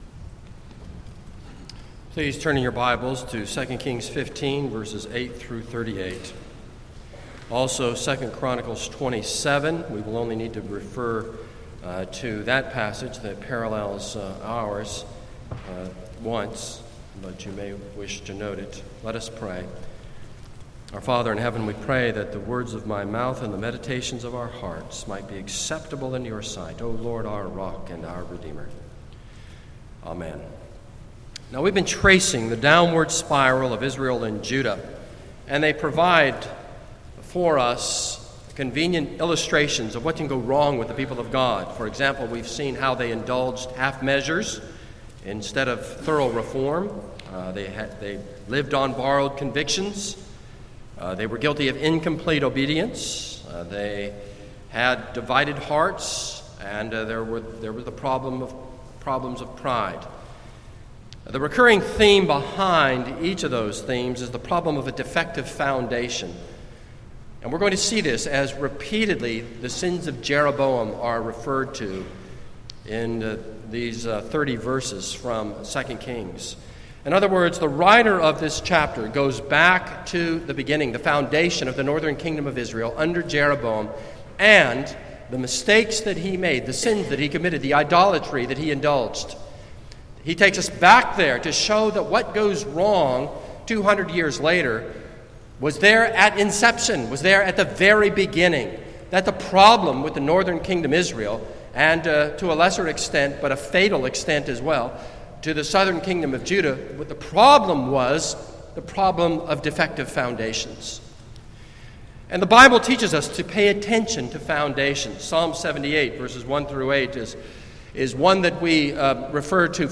This is a sermon on 2 Kings 15:8-38.